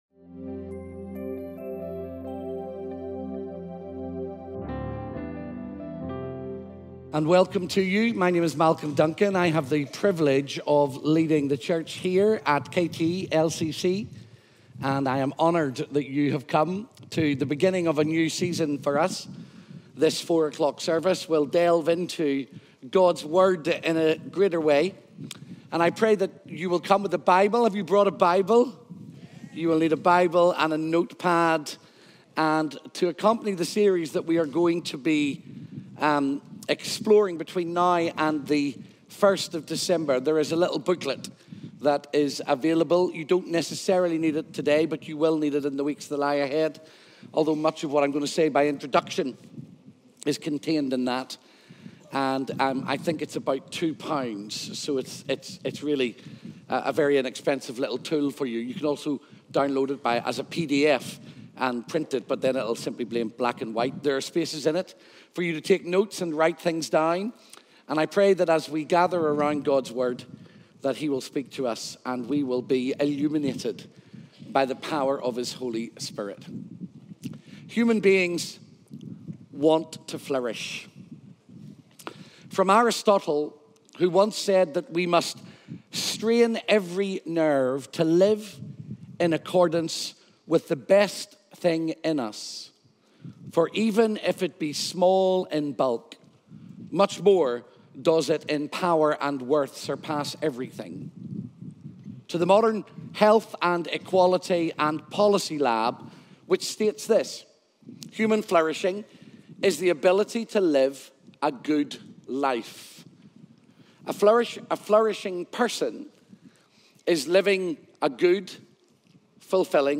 _Sermon Series